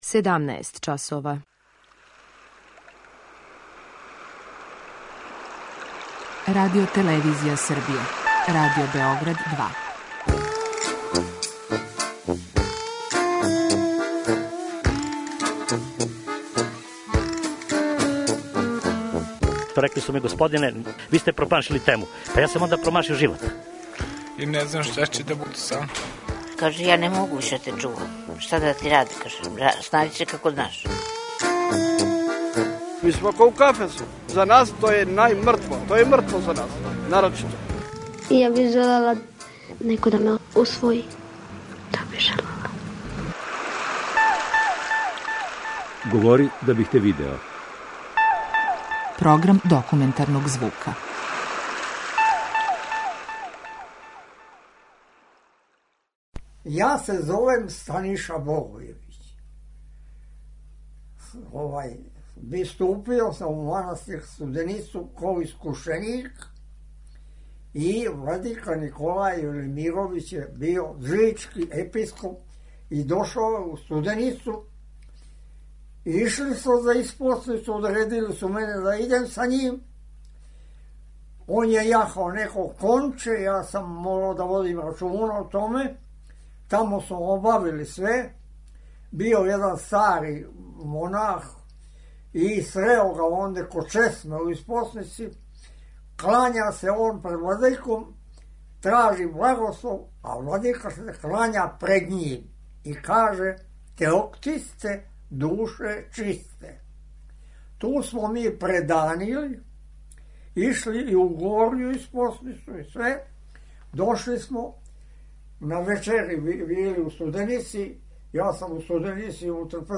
Документарни програм
Снимци су настали пре више од тридесет година.